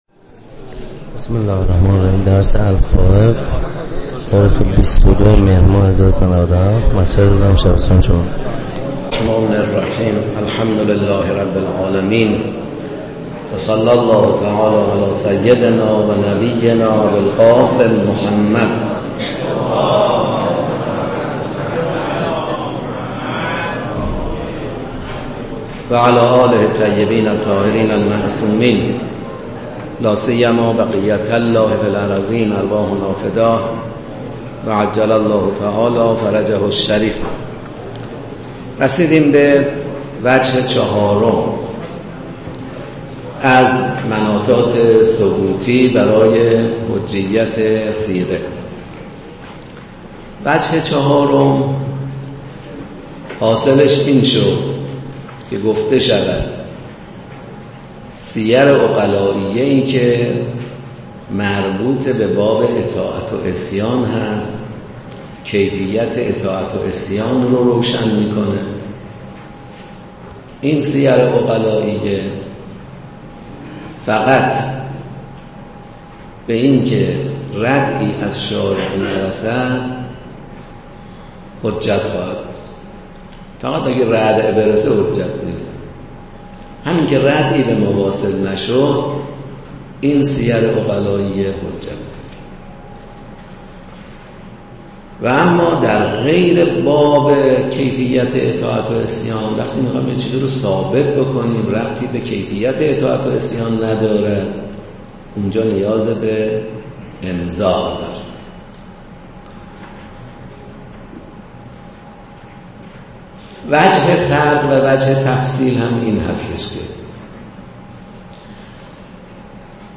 لازم به ذکر است: متن ذیل پیاده شده از صوت درس می‌باشد و هیچگونه ویرایشی روی آن اعمال نشده است.